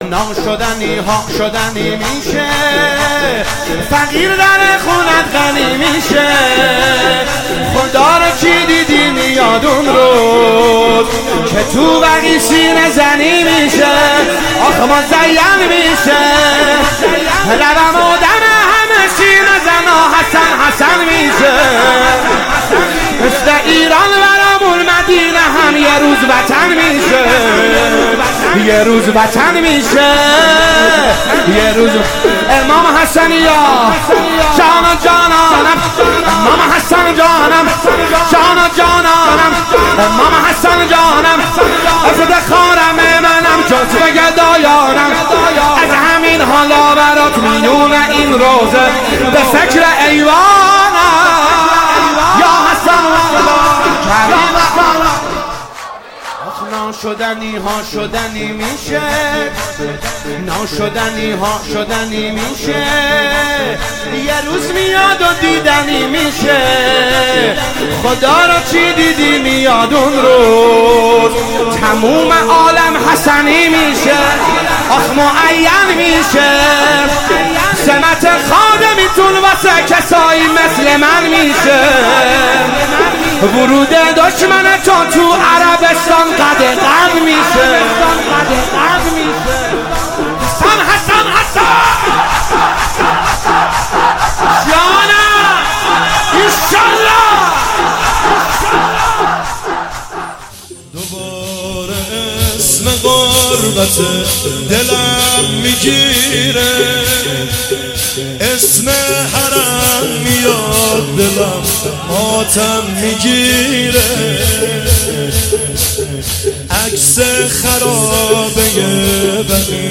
میکس موزیک مداحی سالروز جهانی تخریب قبور ائمه بقیع